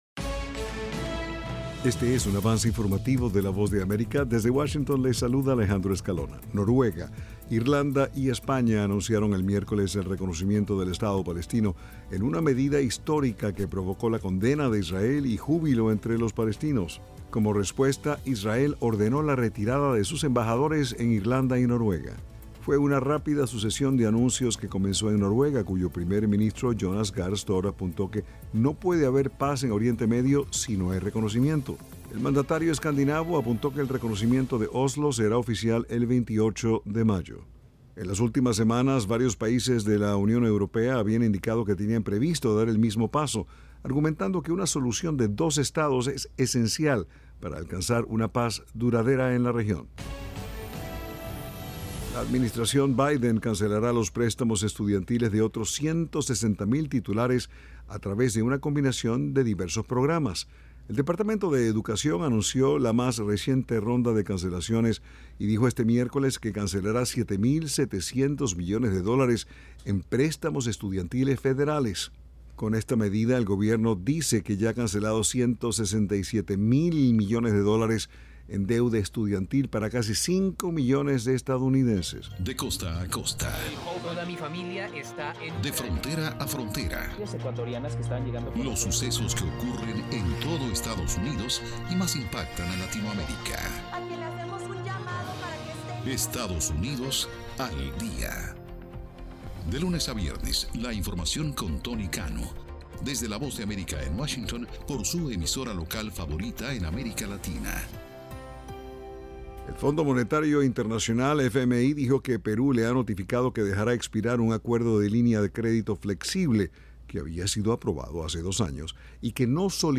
Este es un avance informativo presentado por la Voz de América.